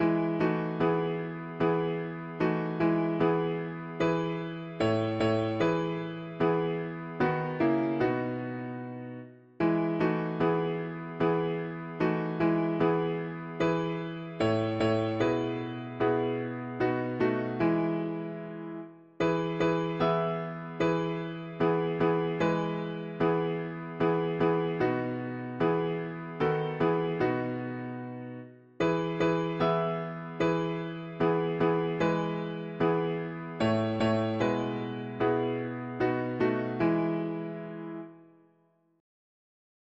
Now I’m resting, swee… english german christian 4part chords